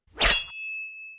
Melee Weapon Attack 3.wav